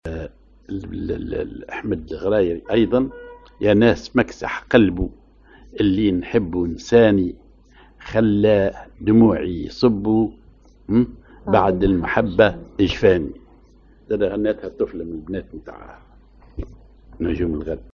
Maqam ar جهاركاه
Rhythm ar نصف وحدة
genre أغنية